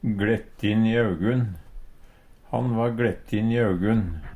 gLettin i augun - Numedalsmål (en-US)